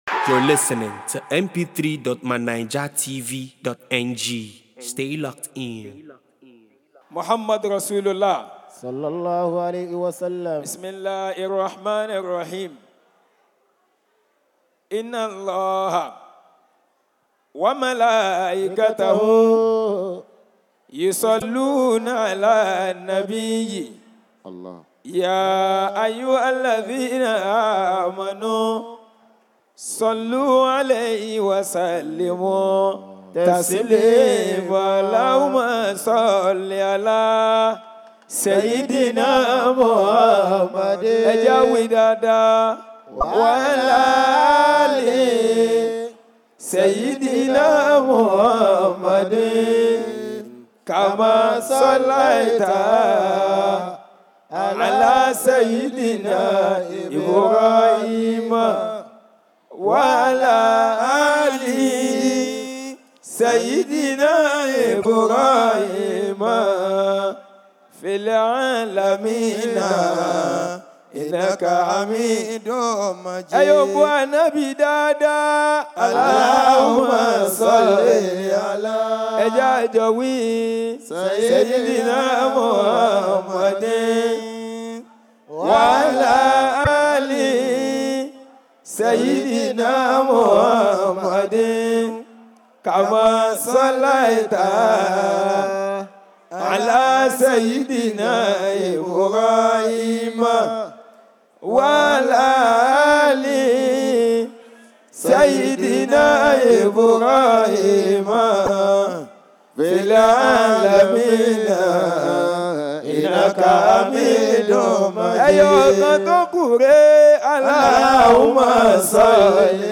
Special Assalatu Prayer